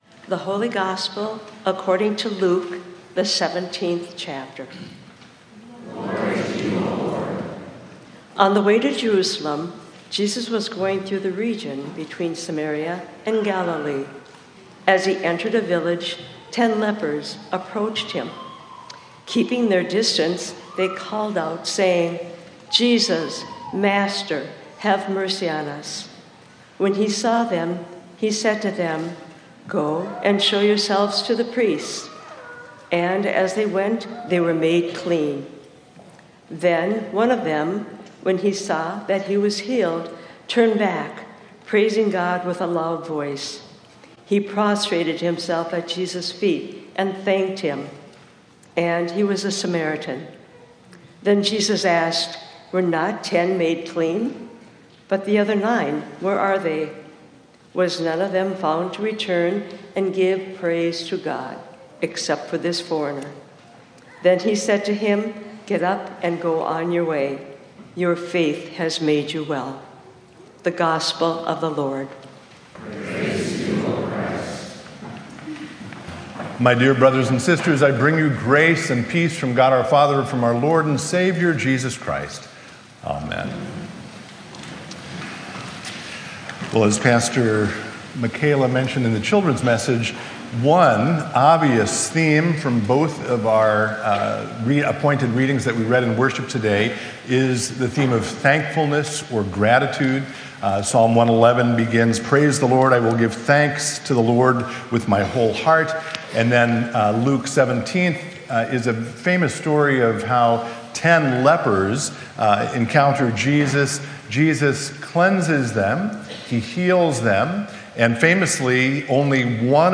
Includes sermons from our Sunday morning 9:45 worship services.